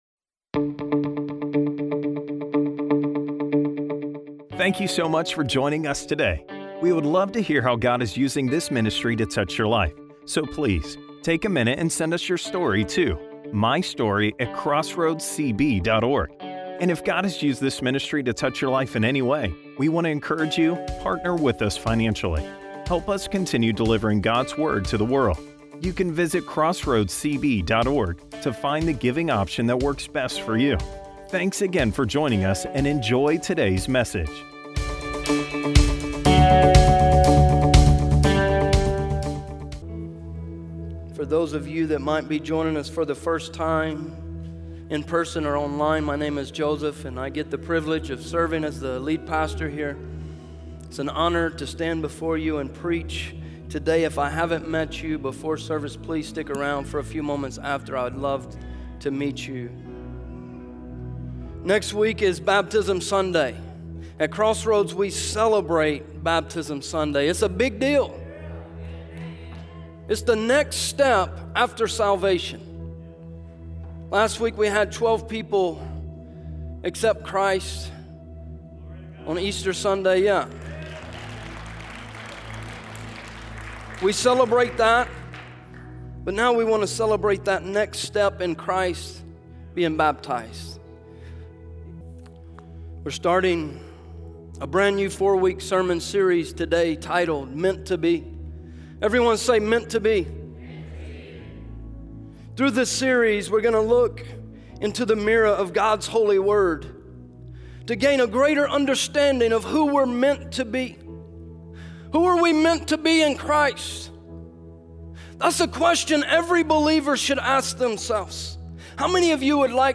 Sermons - Crossroads Church